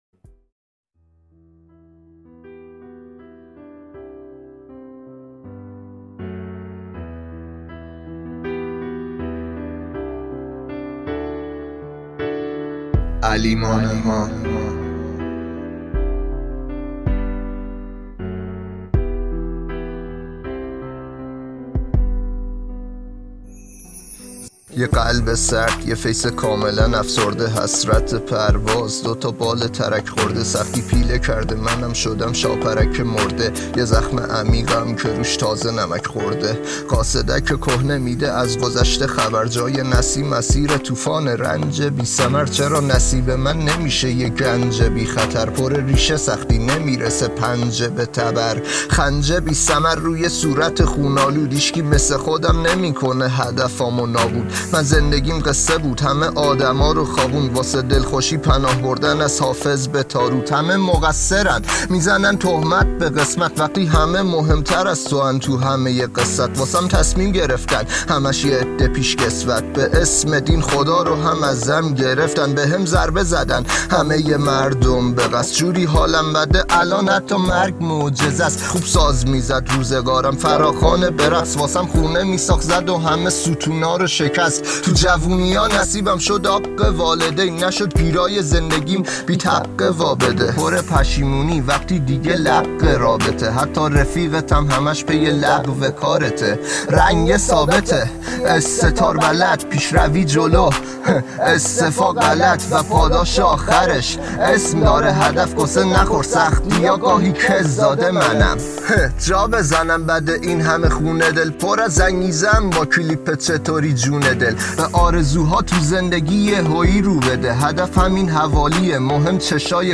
آهنگ جدید رپ
رپ گنگ